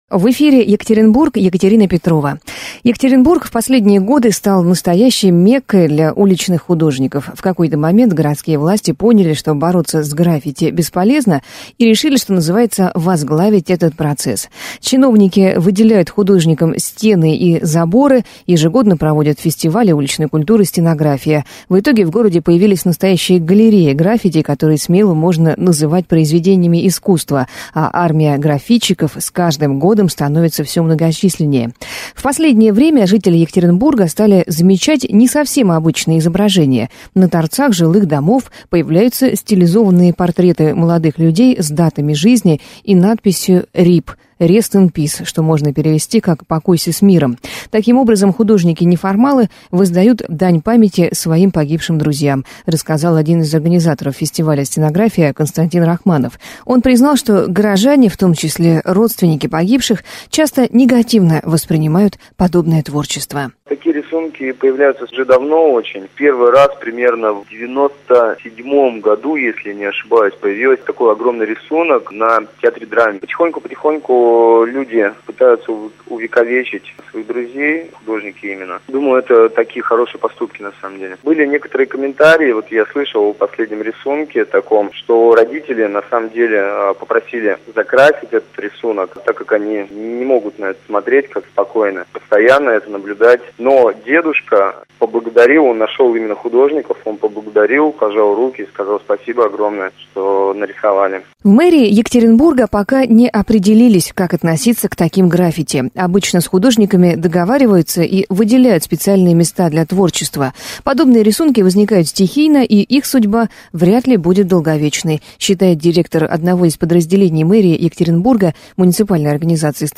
Граффити в Екатеринбурге - репортаж